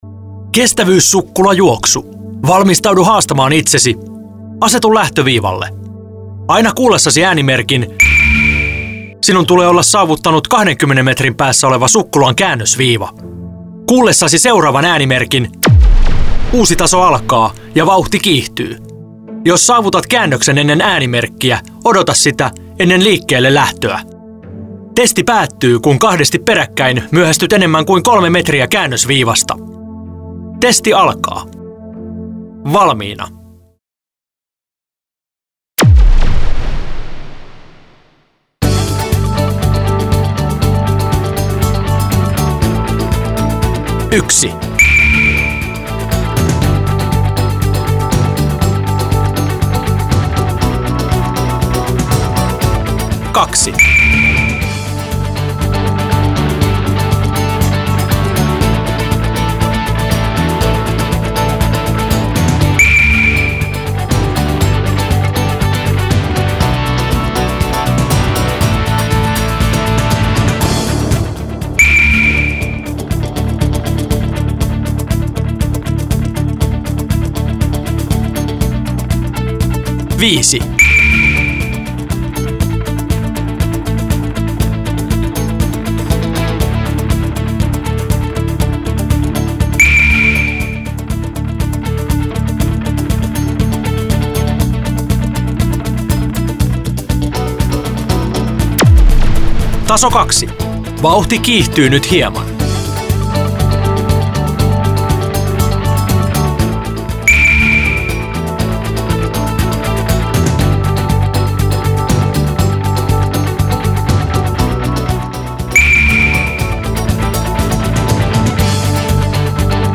Muuta Piip-testin (sukkulajuoksun) äänitallenne ja protokolla seuraympäristössä toteutettavia testejä varten Piip-testin äänitallenne Protokolla ja MAS-nopeuden määrittäminen
sukkulajuoksu.mp3